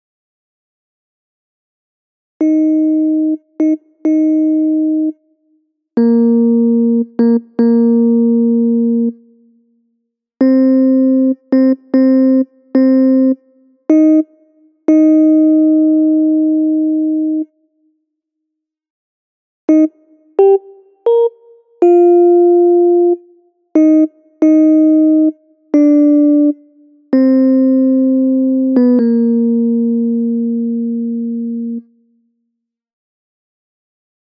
Key written in: E♭ Major
Each recording below is single part only.
Spiritual
Learning tracks sung by